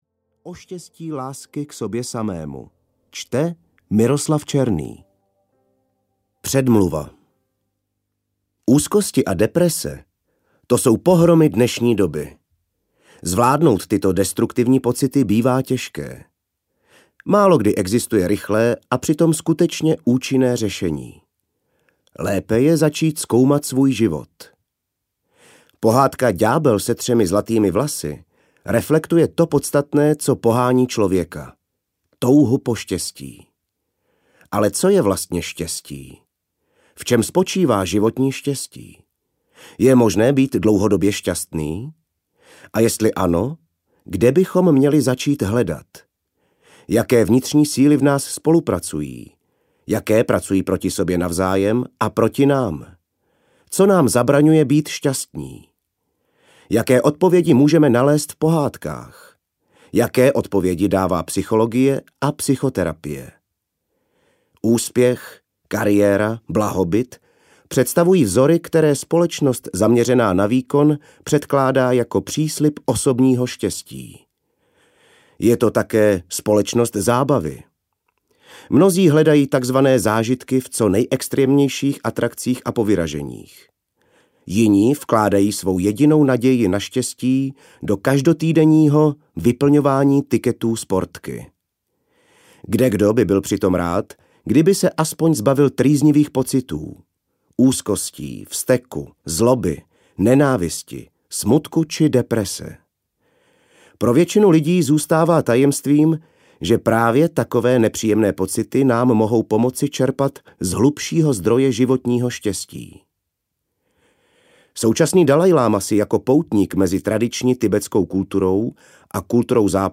Cesty z úzkosti a deprese audiokniha
Ukázka z knihy
cesty-z-uzkosti-a-deprese-audiokniha